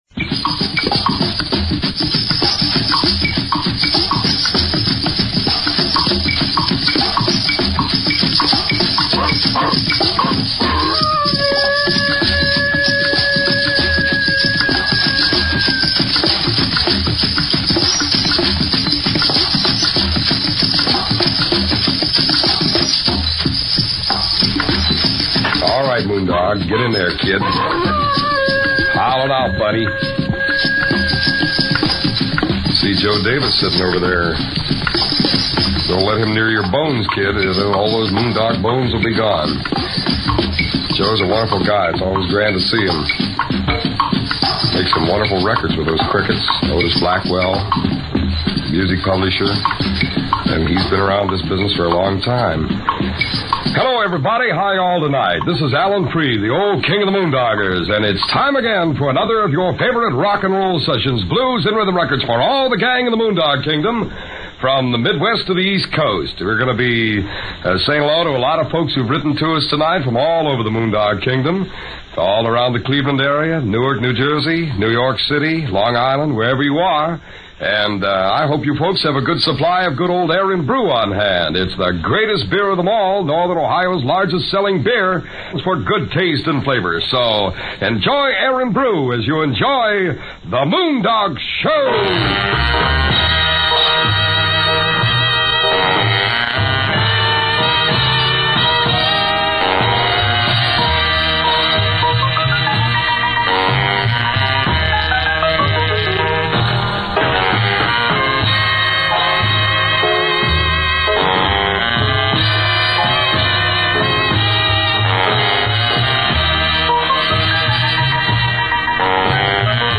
Alan-Freed-The-Moondog-Show-Cleveland-WJW-1954.mp3